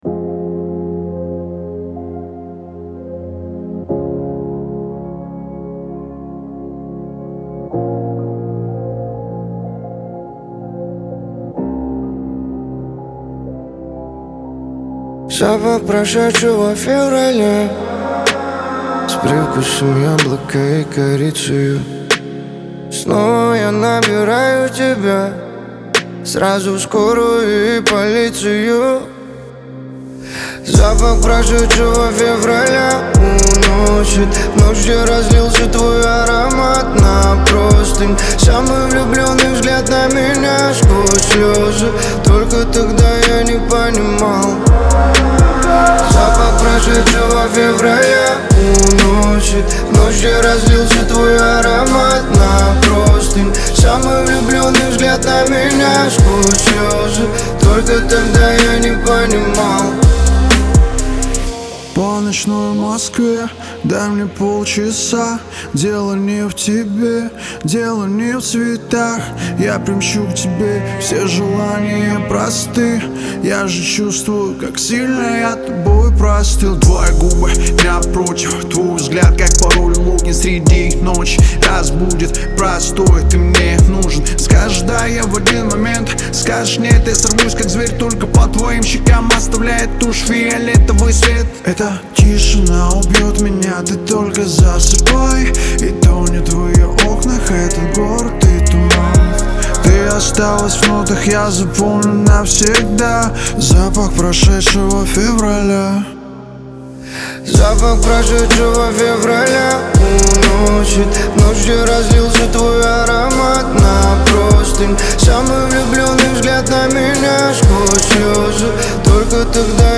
это трек в жанре рэп с элементами мелодичного хип-хопа.